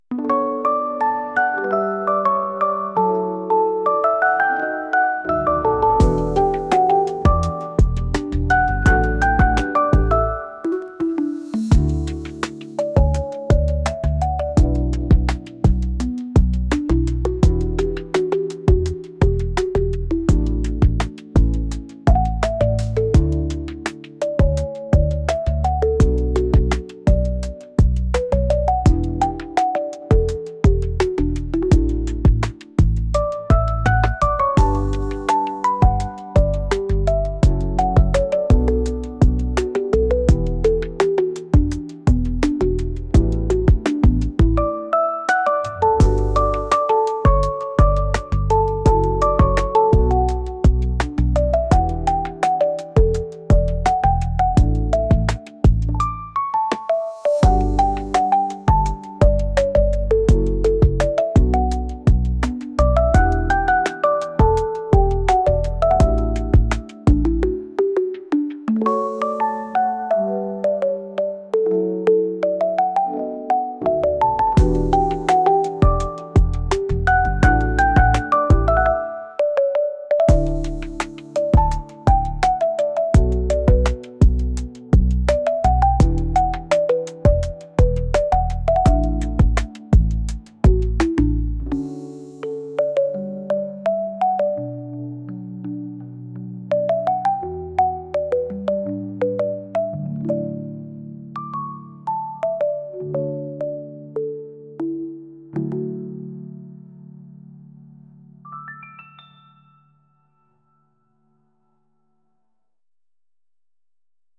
一日を振り返って幸せをかみしめるまったりした曲です。